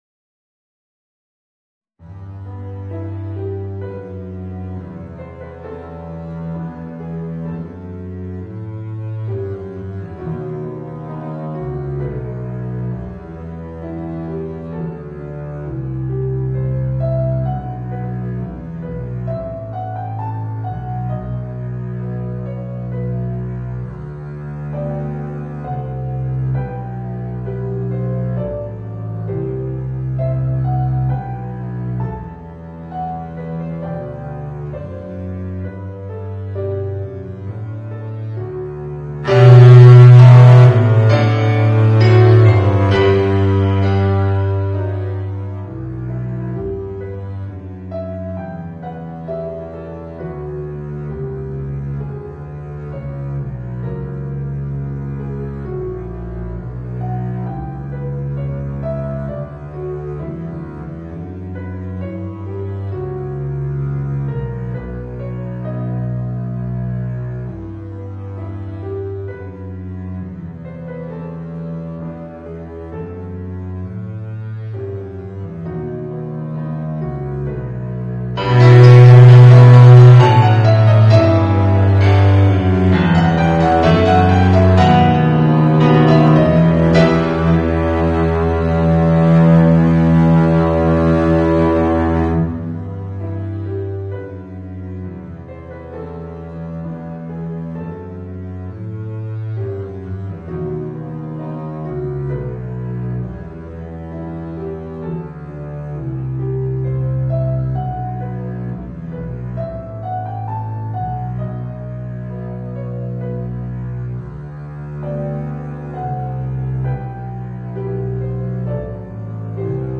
Voicing: Contrabass and Organ